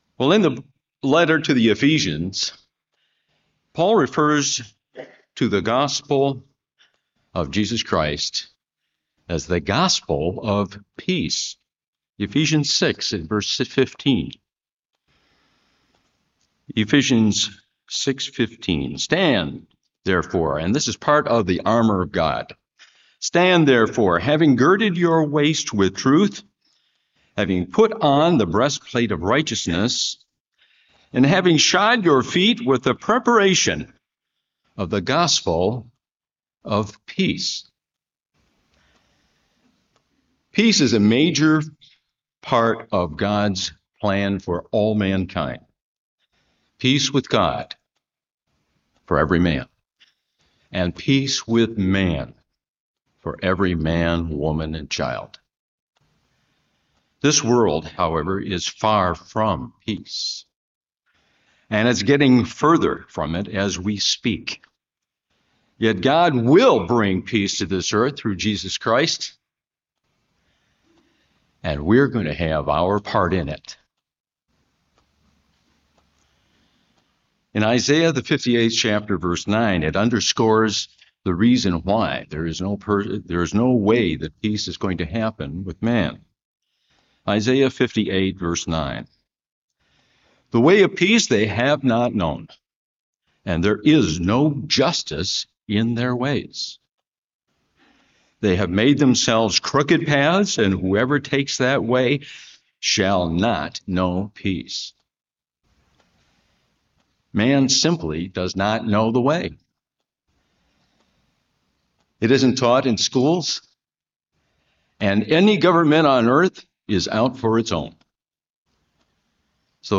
Sermons
Given in Aransas Pass, Texas 2025